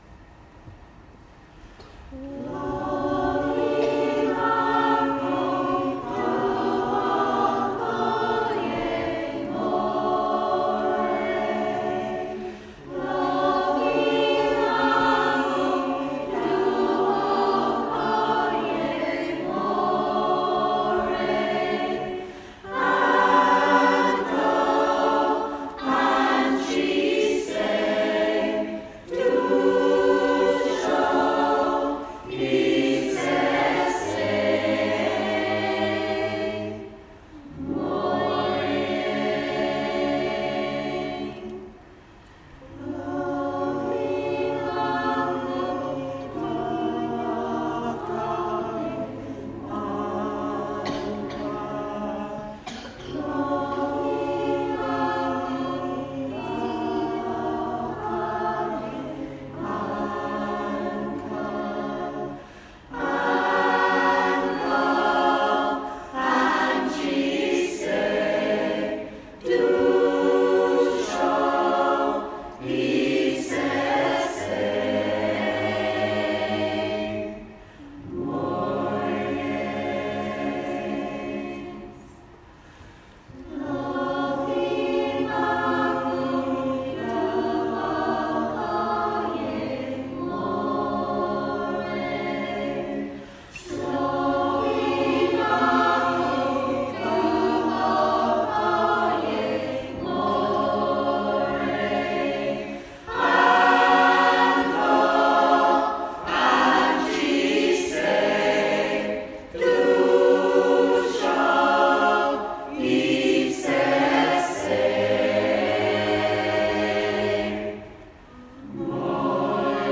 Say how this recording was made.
Charity Event at Holy Trinity Church 2018 We held a charity event at Holy Trinity Church Knaresborough in aid of the Food bank and Harrogate MS society. Below are some recordings from the evening